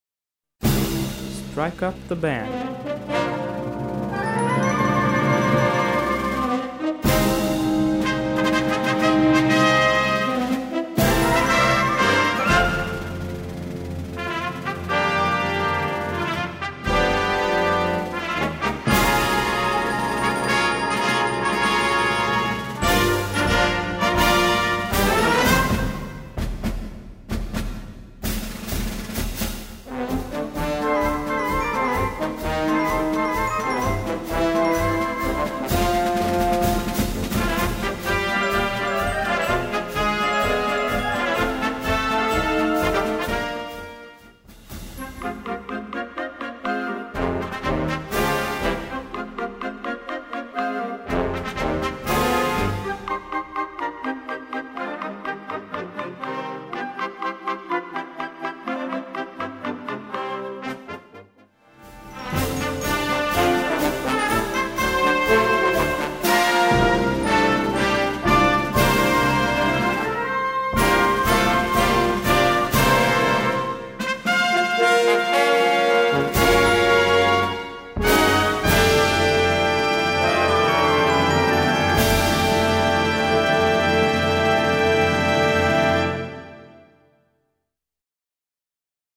Gattung: Marsch
Besetzung: Blasorchester
prickelnde Version für modernes Blasorchester